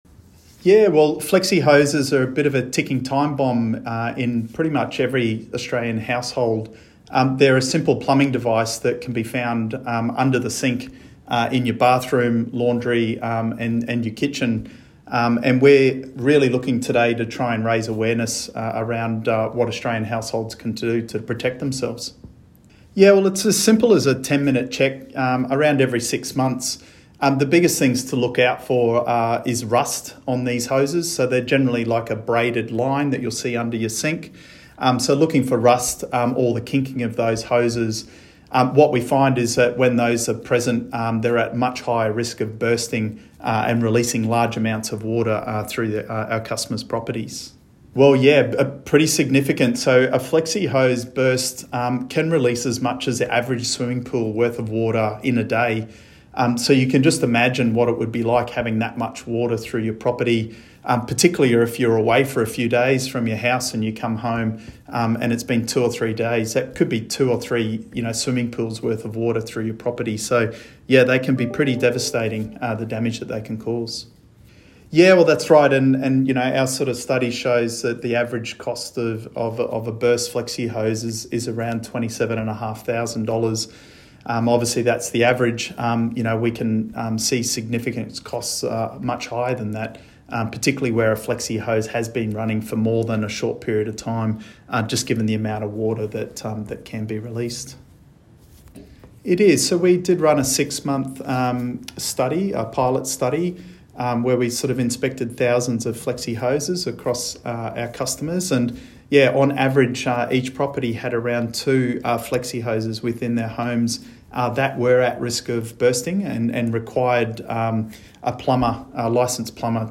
Radio Grabs